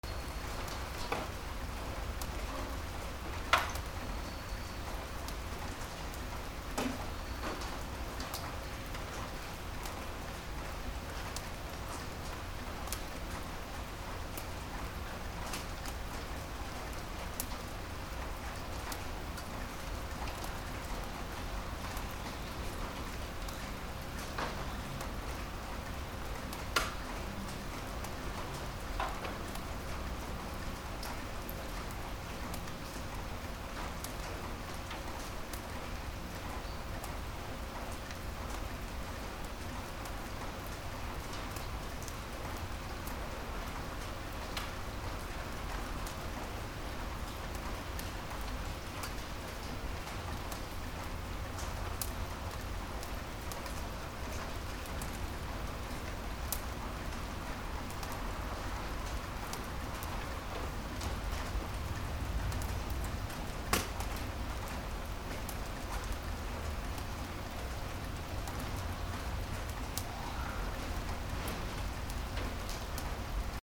/ A｜環境音(天候) / A-30 ｜雨 道路
雨 道路 交通量小
アンビエンス 自転車通過 サー